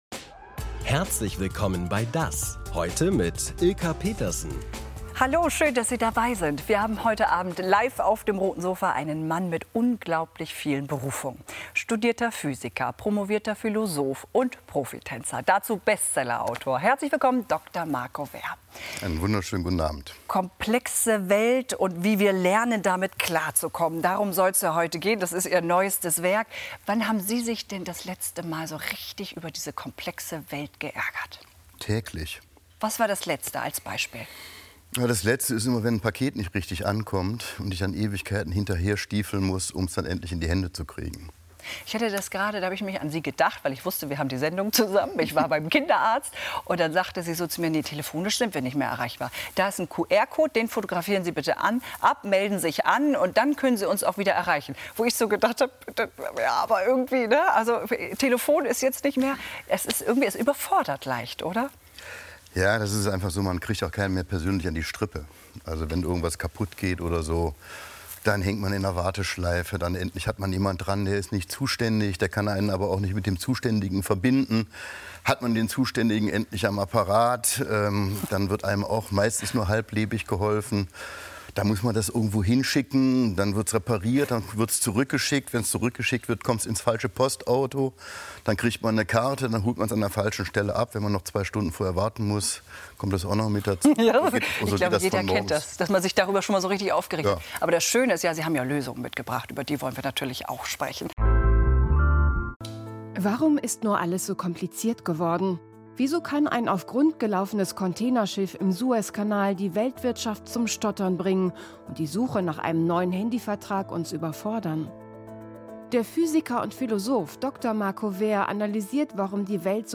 DAS! ist bekannt für intensive Interviews mit prominenten und kompetenten Gästen auf dem Roten Sofa. Die Gesprächsfassung (ohne Filmbeiträge) vom Vortag gibt es auch als Audio-Podcast.